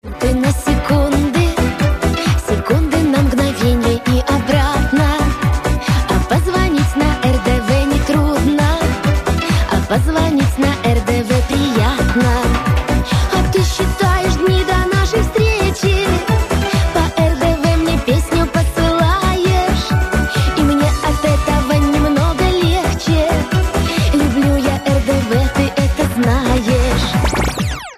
Заставочки